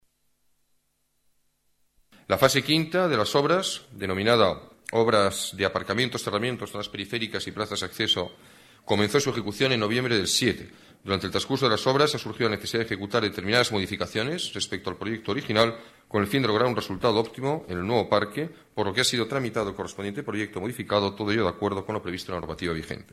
Nueva ventana:Declaraciones del alcalde, Alberto Ruiz-Gallardón, sobre el Parque Forestal de Valdebebas